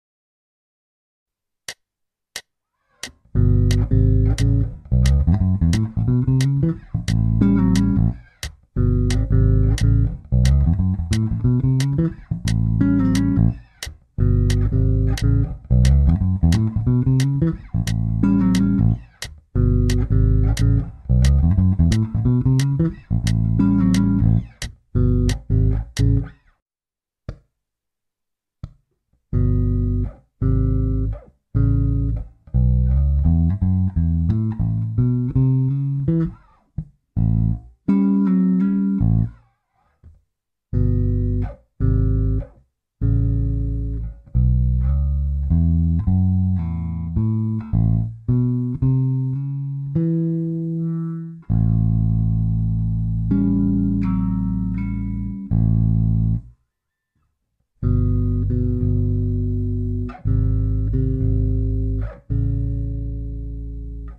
L03 Bluesy pentatonic bass lick in A7
A7 Bluesy riff using the A major pentatonic scale and double stops.
L03A7Bluesyriff.mp3